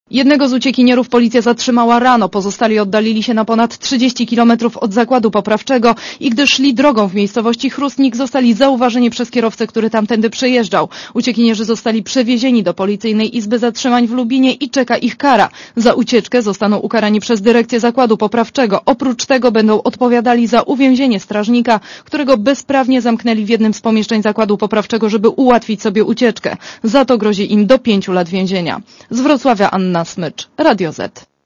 Posłuchaj relacji reporterki Radia Zet (122 KB)